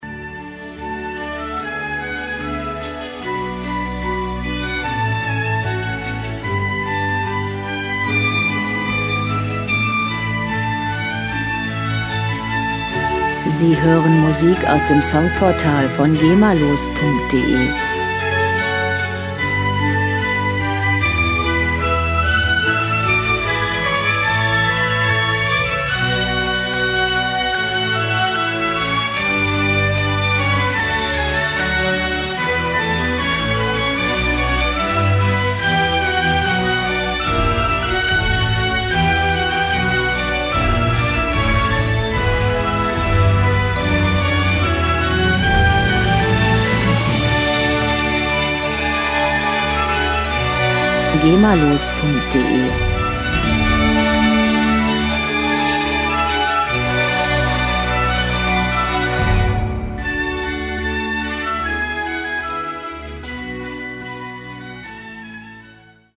• Filmmusik